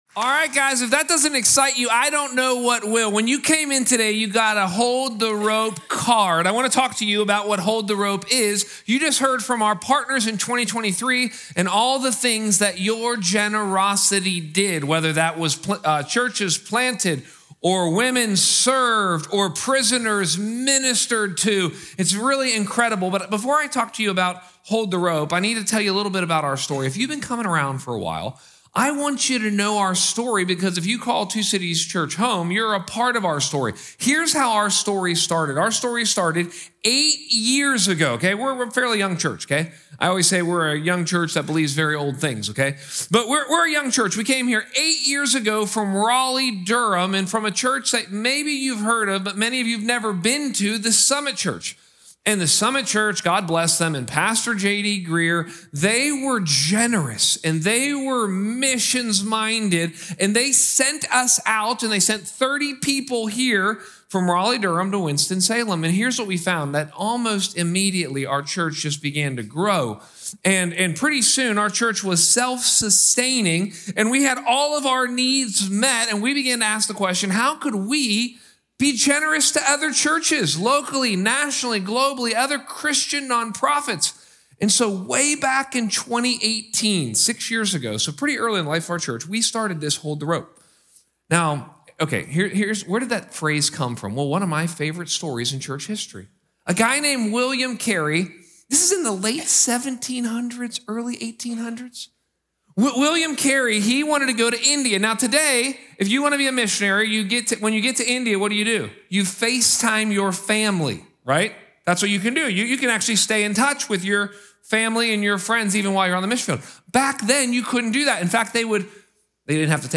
Weekly Messages from Two Cities Church in Winston Salem NC.